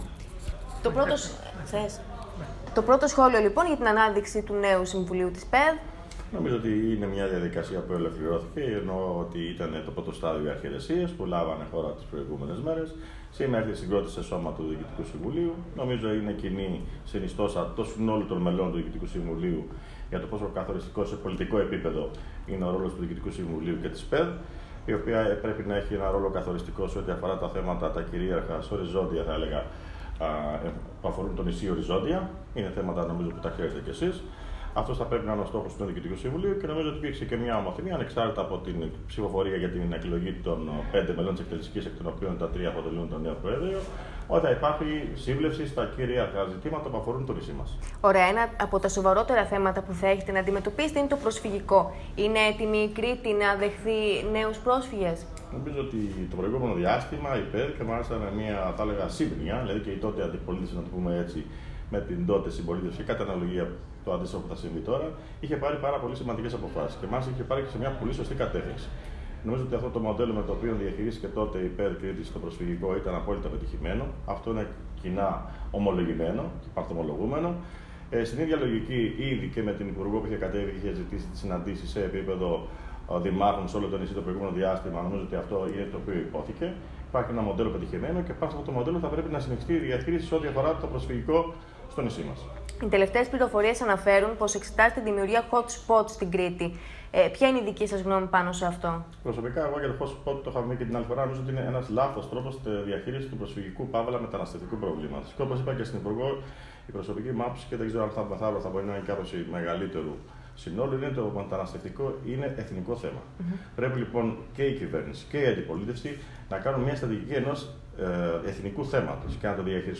Ακούστε εδώ το νέο γραμματέα της ΠΕΔ και Δήμαρχο Πλατανιά Γιάννη Μαλανδράκη: